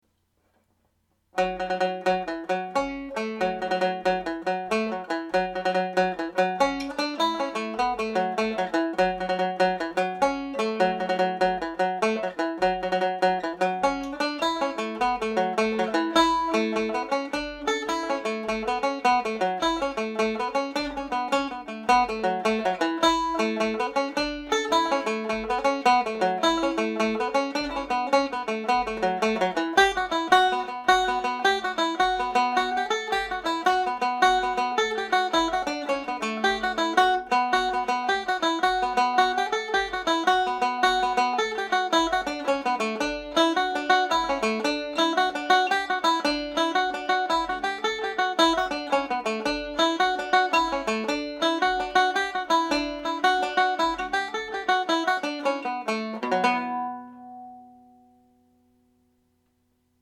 This is the second tune of the set of two slip jigs paired with Cathal McConnell’s. The highlighted F# can be played or left out.